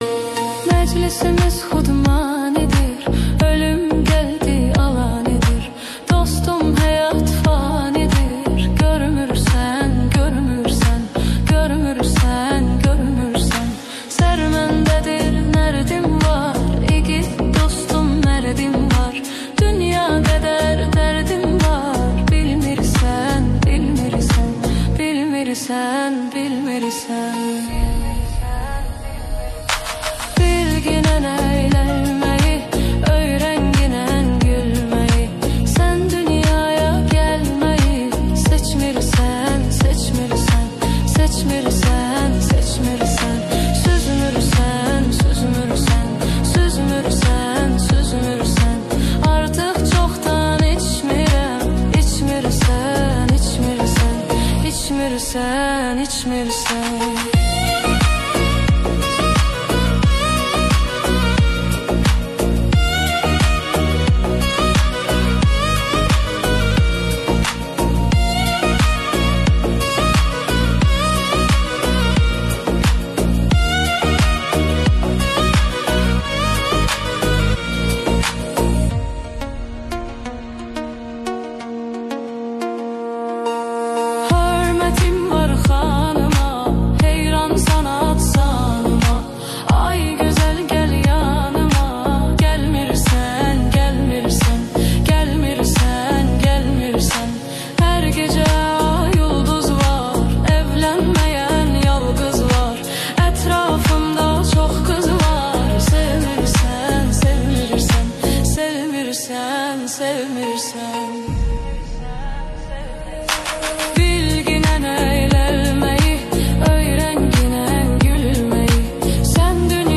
(women)